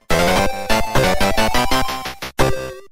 Post-boss cutscene Complete! jingle